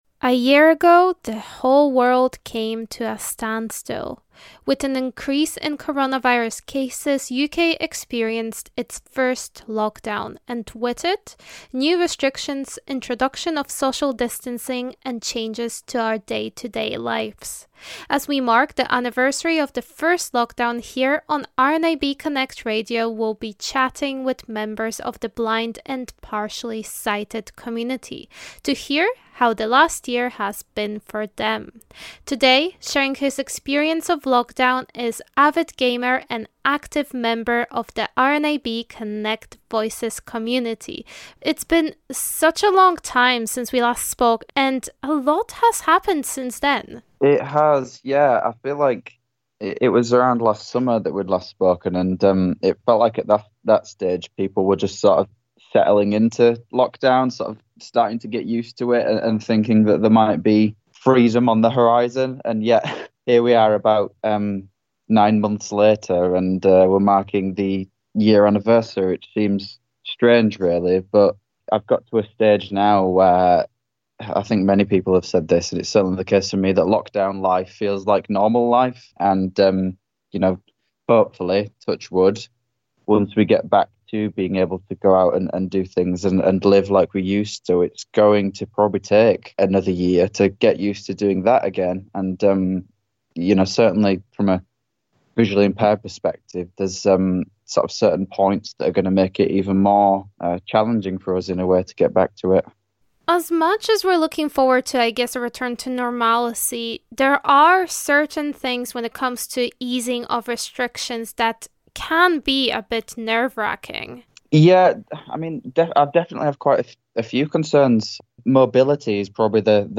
As we mark the anniversary of the first lockdown, here on RNIB Connect Radio, we'll be chatting with members of the blind and partially sighted community to hear how the last year has been for them.